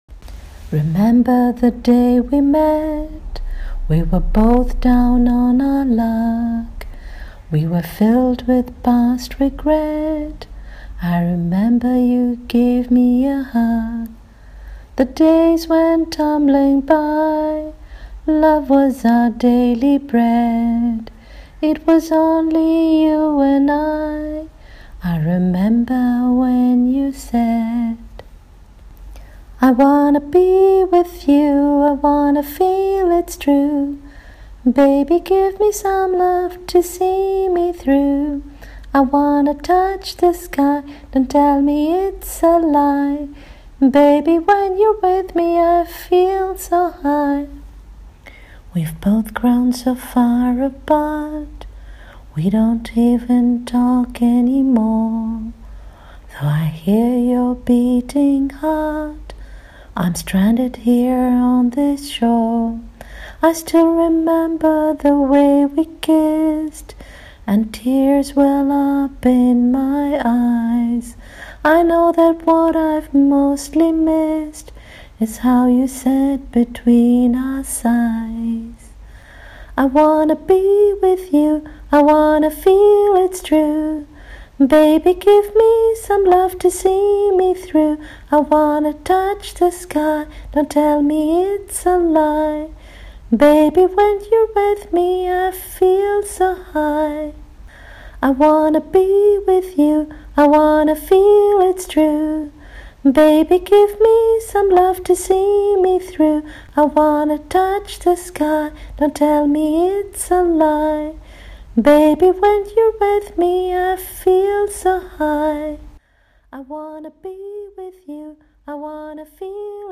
Singing of the lyrics: